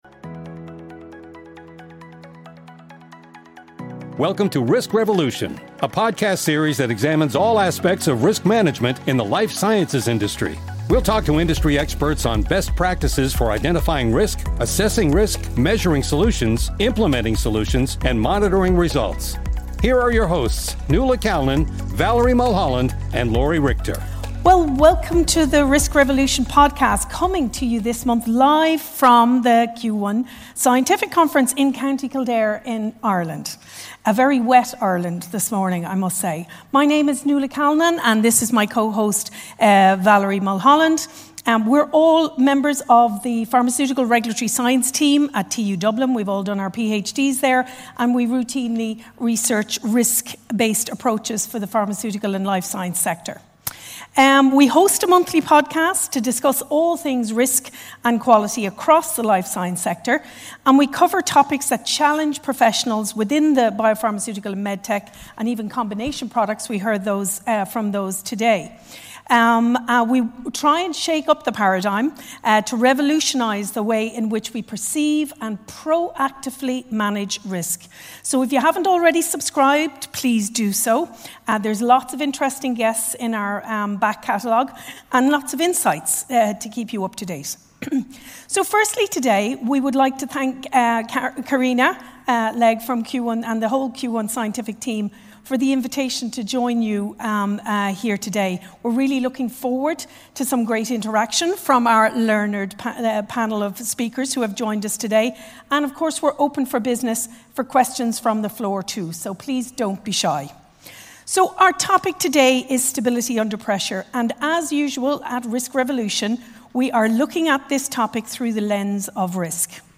LIVE from the Q1 Scientific Conference in Co. Kildare, Ireland